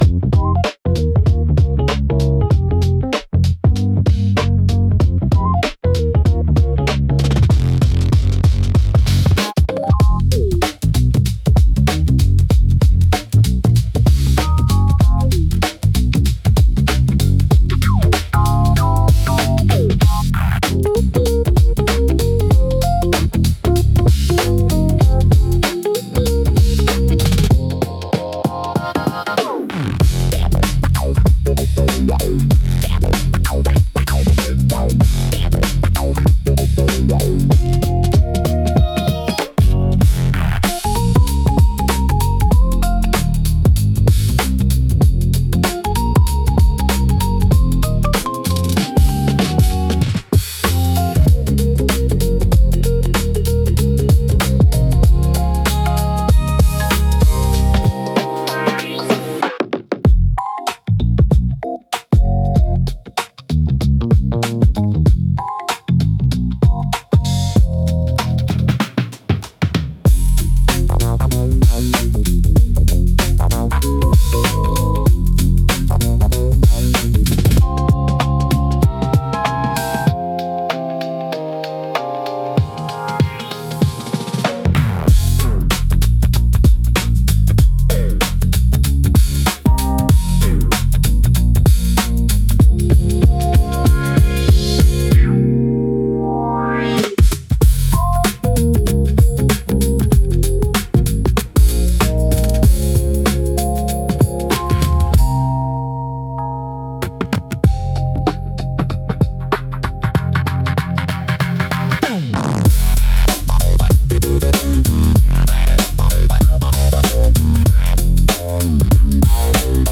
イメージ：インスト,ヒップホップ,ローファイ,ブーム・バップ
インストゥルメンタル（instrumental）